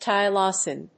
tylosin.mp3